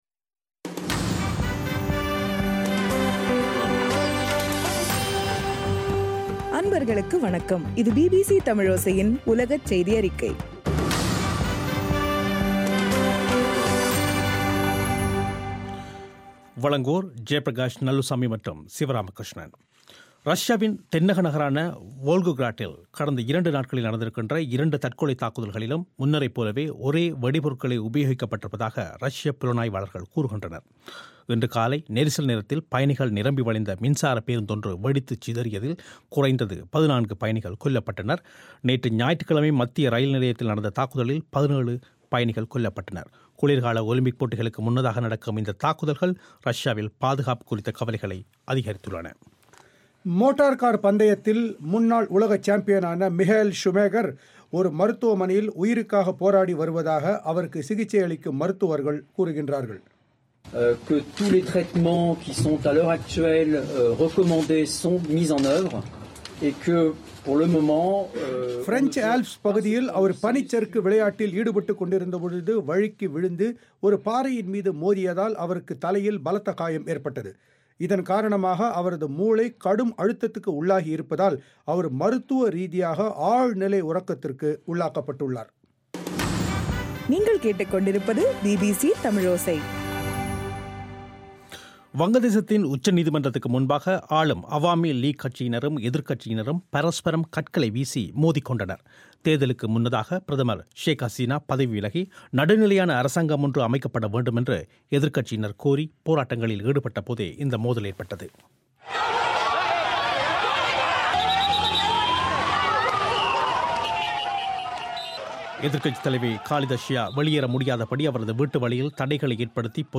பிபிசி தமிழோசையின் உலகச் செய்தியறிக்கை - டிசம்பர் 30